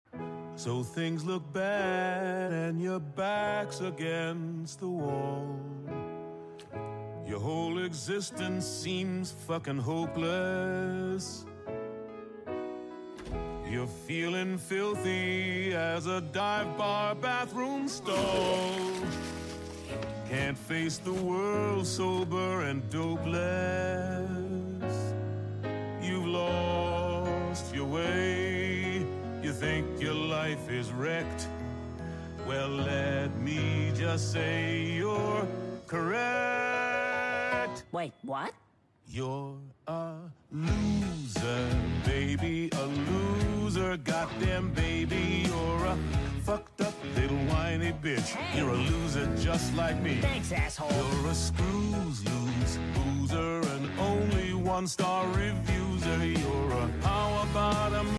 sing-a-long